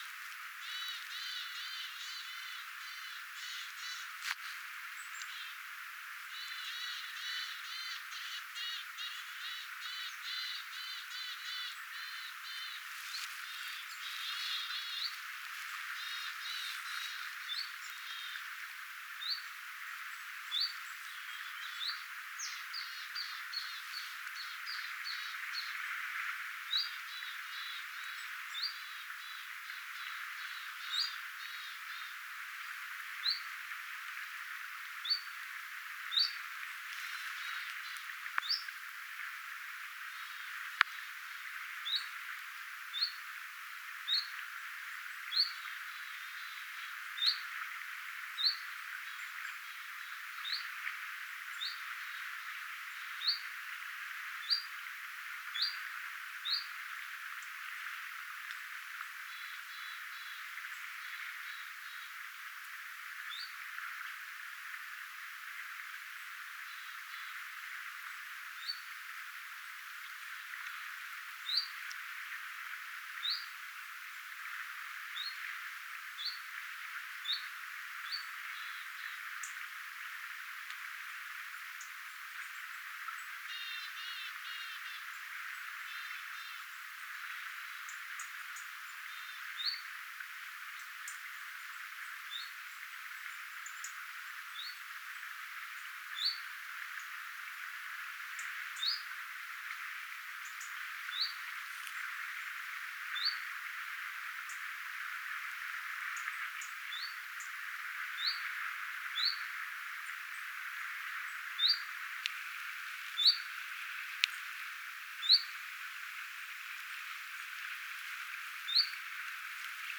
pikkulintuparvi
pikkulintuparvi_etelapuolella_homotiaisia_ainakin.mp3